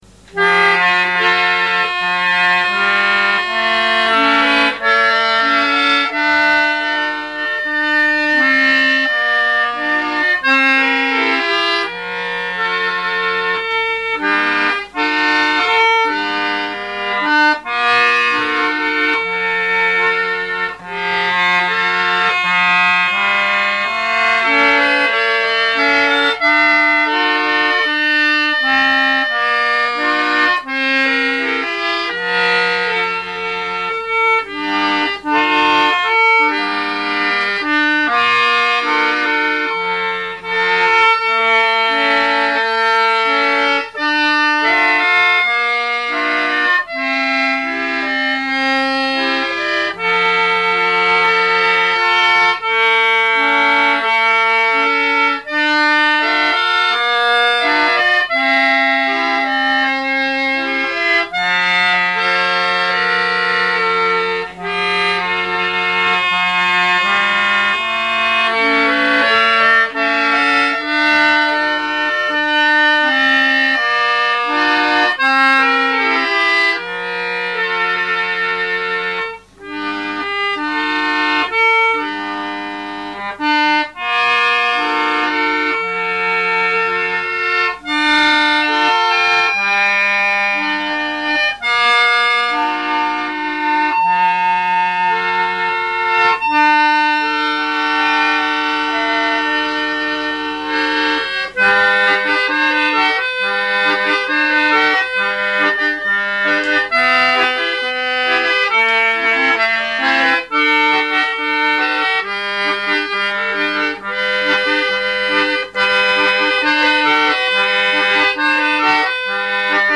all "single take" onto a mini Sanyo cassette recorder
are played on a 48 button ebony ended New Model Lachenal